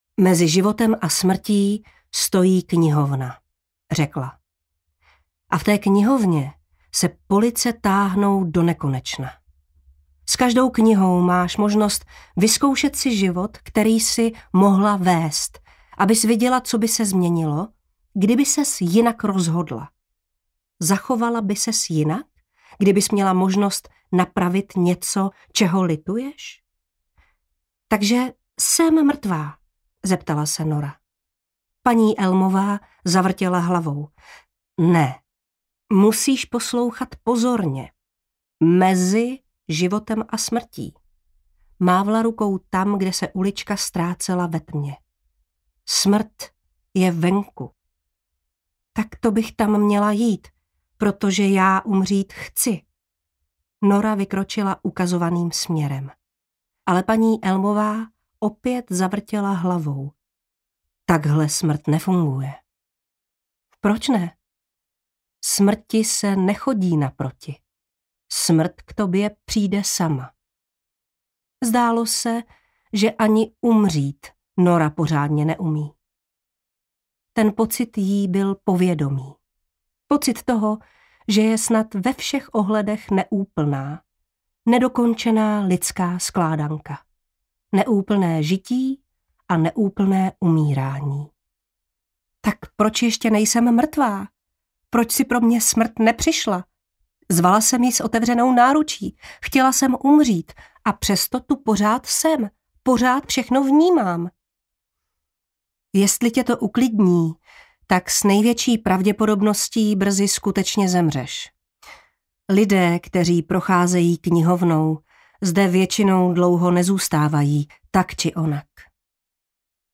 Obálka audioknihy Půlnoční knihovna